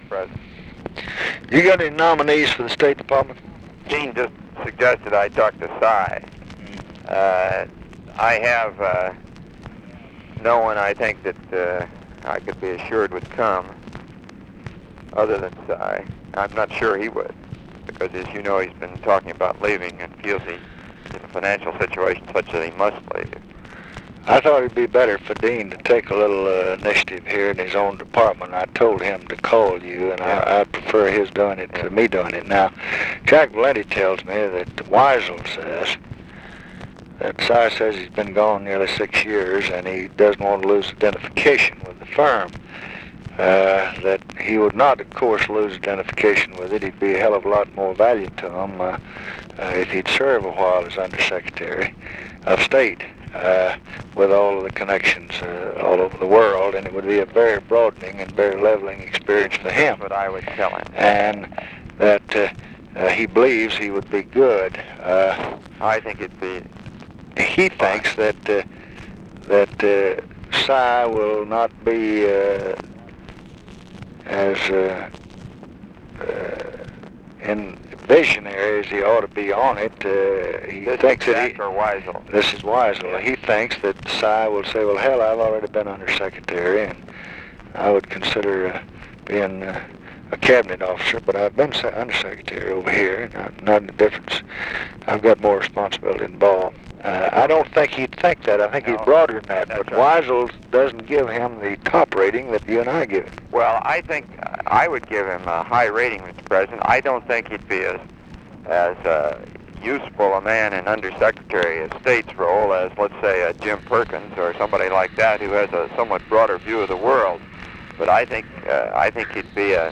Conversation with ROBERT MCNAMARA, August 10, 1966
Secret White House Tapes